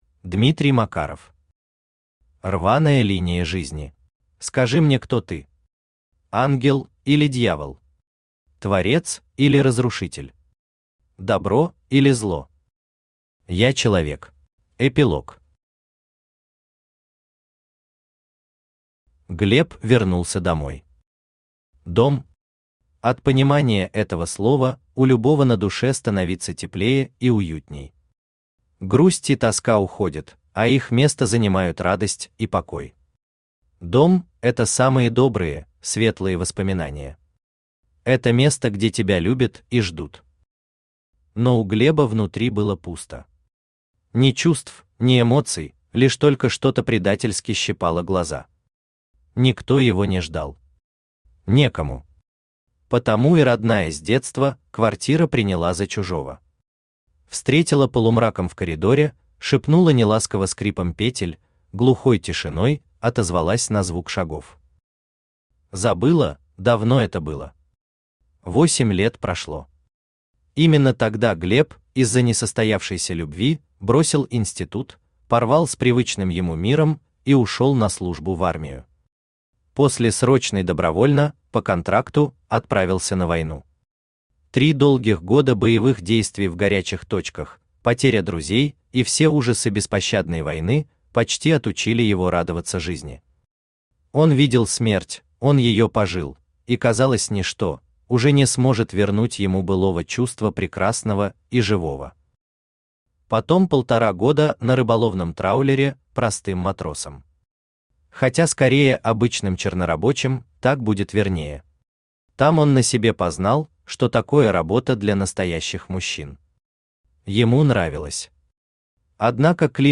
Аудиокнига Рваная линия жизни | Библиотека аудиокниг
Aудиокнига Рваная линия жизни Автор Дмитрий А. Макаров Читает аудиокнигу Авточтец ЛитРес.